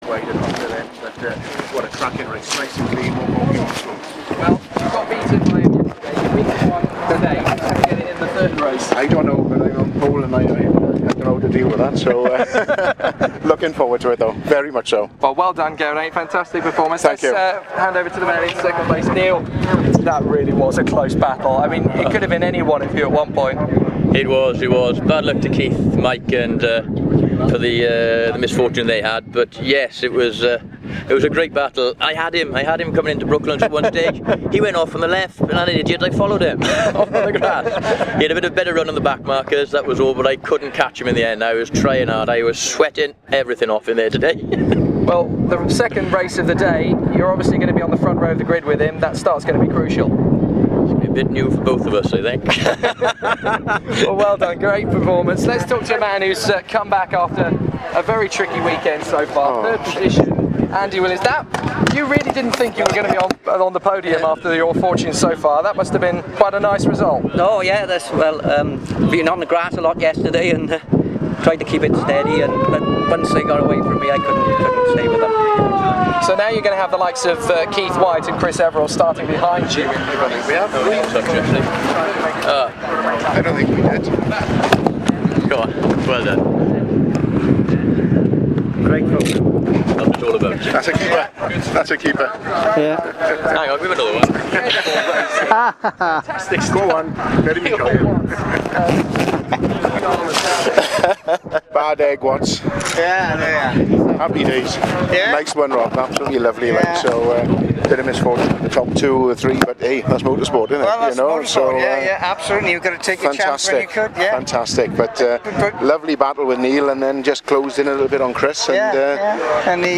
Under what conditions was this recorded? (apologies for the poor quality)